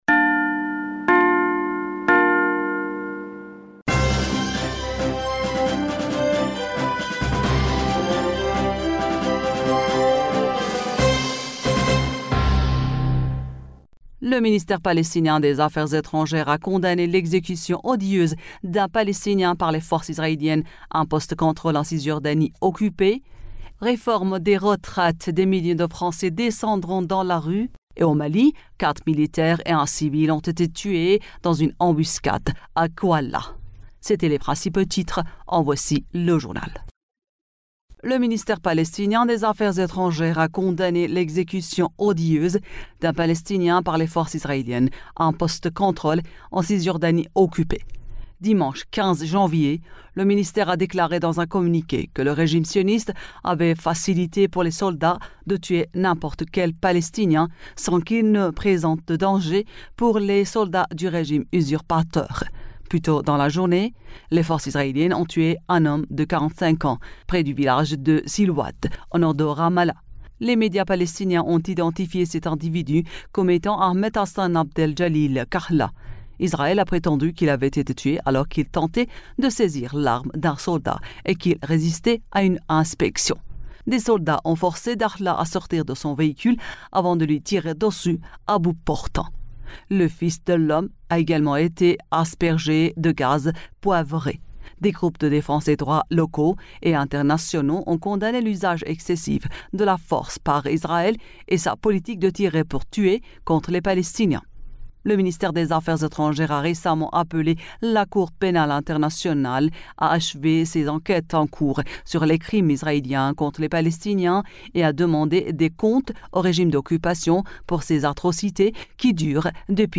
Bulletin d'information du 16 Janvier